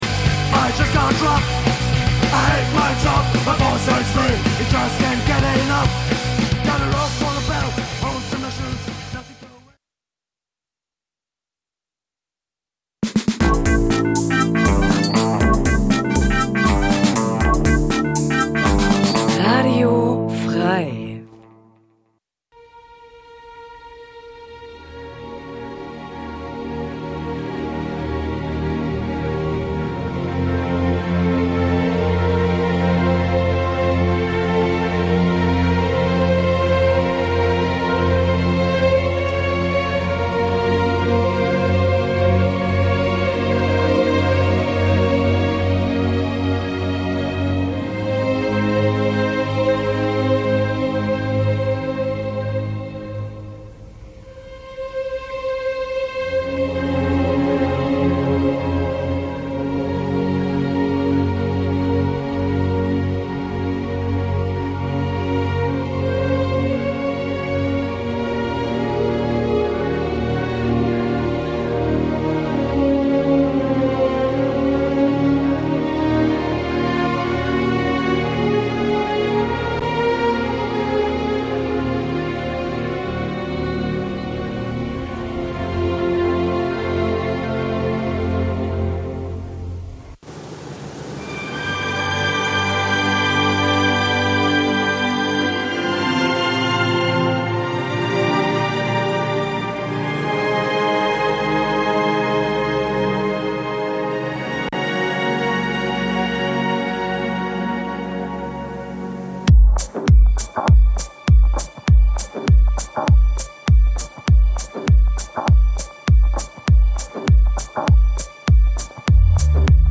Musiksendung Dein Browser kann kein HTML5-Audio.